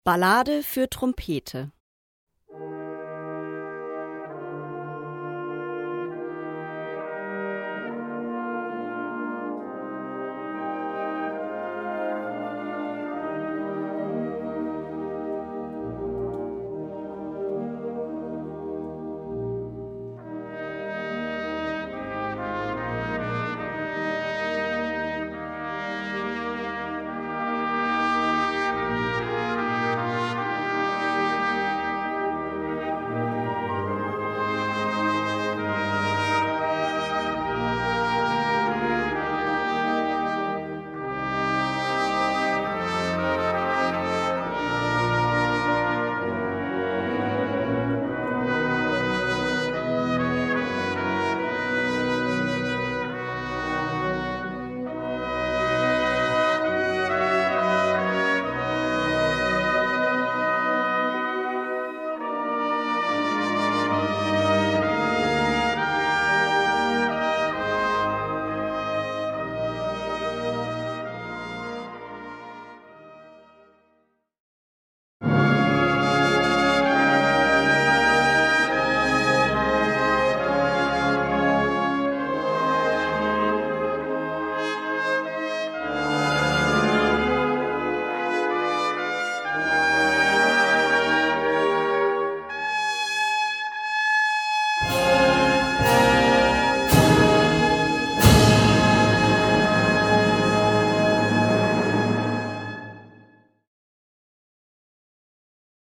5:20 Minuten Besetzung: Blasorchester Tonprobe